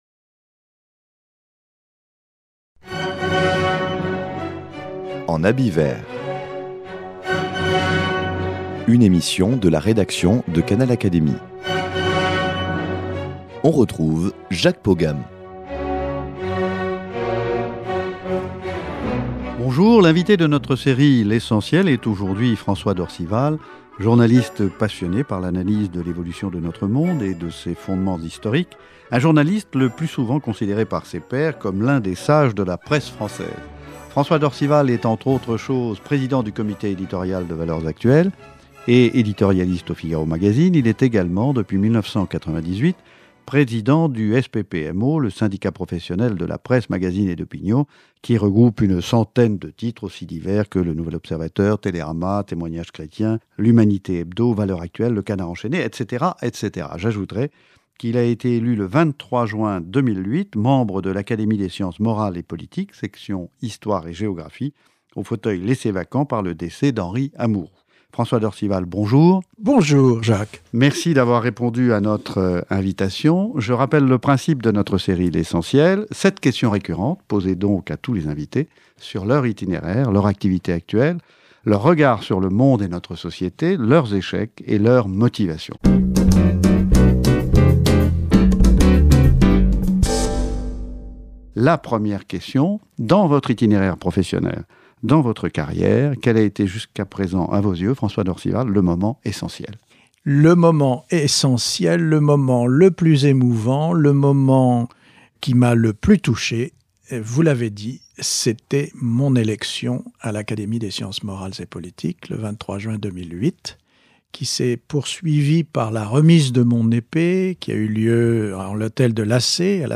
Il passe ici de l’autre côté du micro pour s’exprimer sur la société d’aujourd’hui et se confier sur son métier et sur les moments forts de sa vie, notamment son engagement en Algérie.
L’invité de notre série L’Essentiel avec... est aujourd’hui François d’Orcival, journaliste passionné par l’analyse de l’évolution de notre monde et de ses fondements historiques.